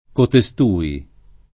cotestui [ kote S t 2 i ]